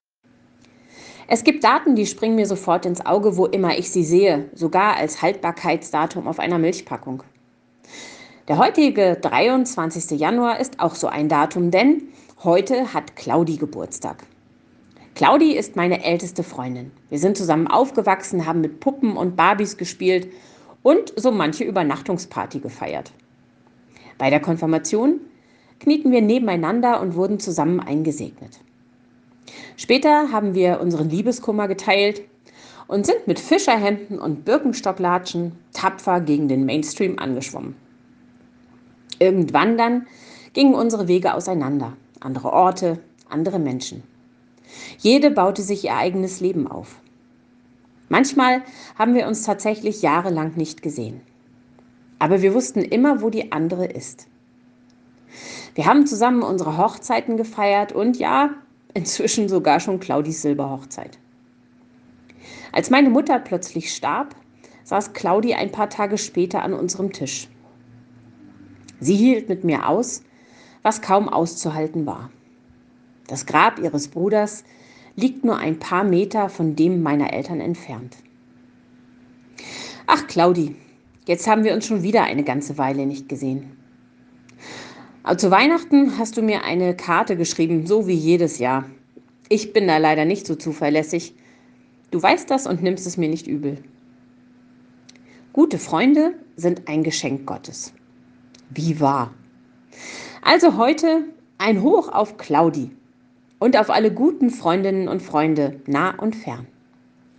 Andacht anhören Andacht lesen Fotos